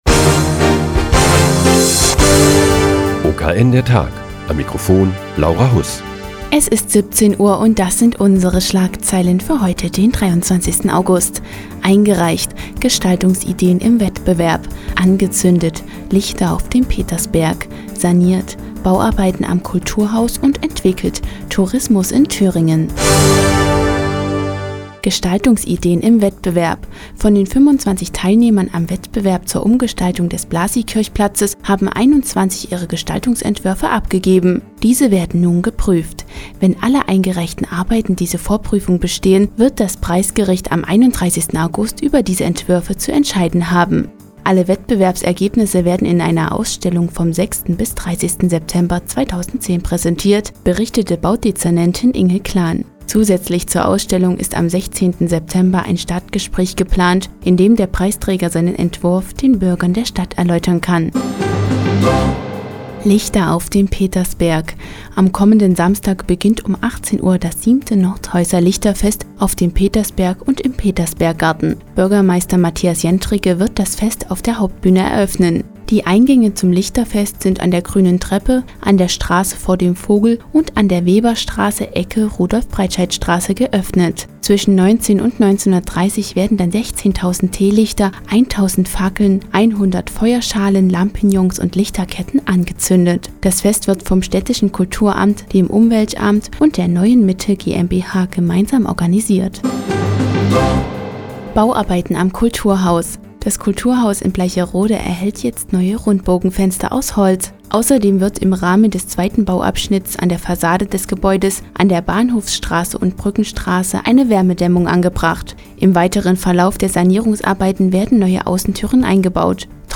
Die tägliche Nachrichtensendung des OKN ist nun auch in der nnz zu hören. Heute geht es um das 7. Nordhäuser Lichterfest und die Bauarbeiten am Kulturhaus in Bleicherode.